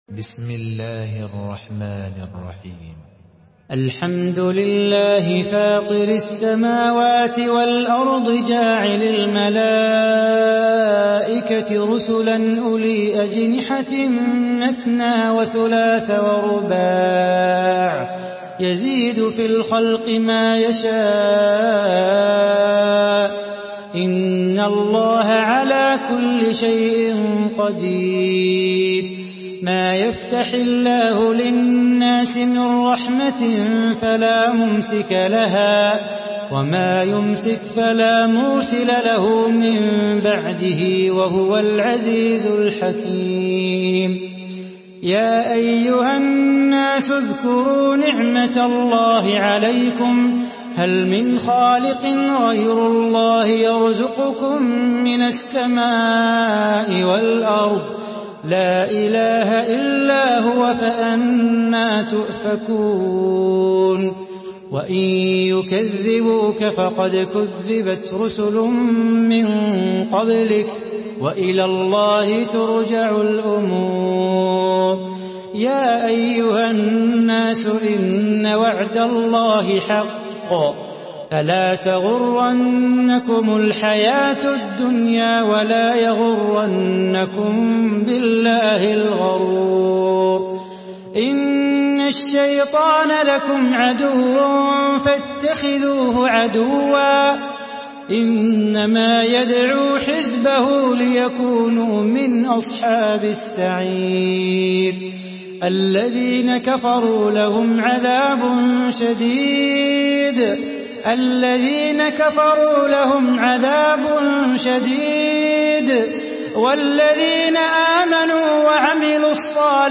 Surah Fatir MP3 in the Voice of Salah Bukhatir in Hafs Narration
Listen and download the full recitation in MP3 format via direct and fast links in multiple qualities to your mobile phone.